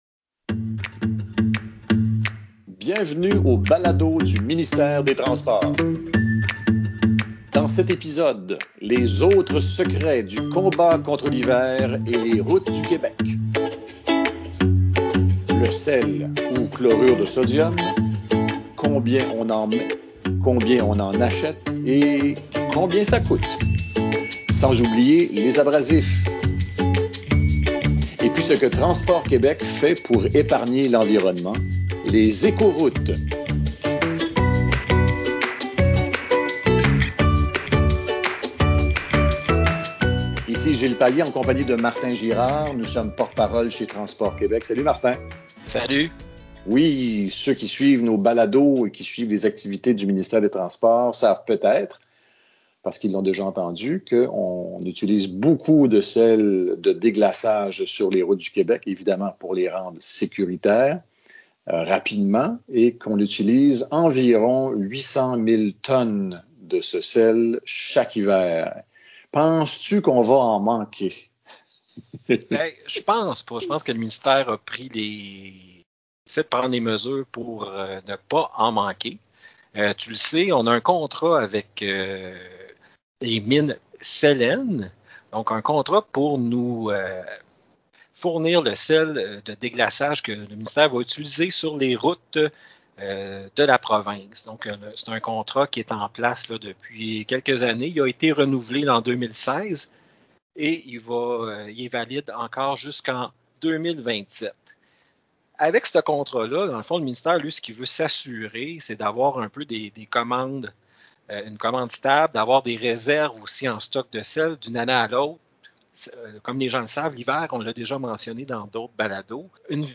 Un balado assaisonné d’informations pertinentes, qui arrive à point!